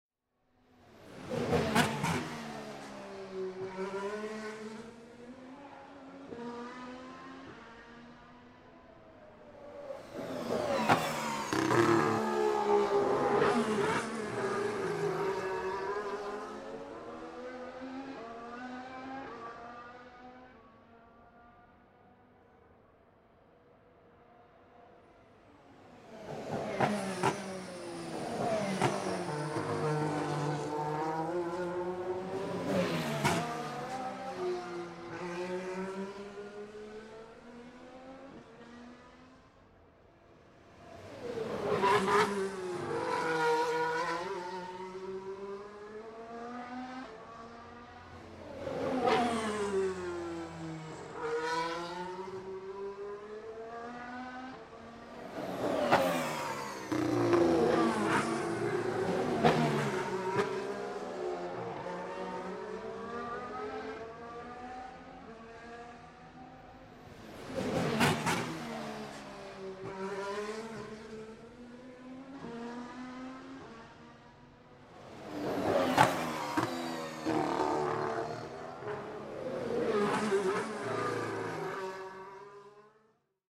Montreal F1 practice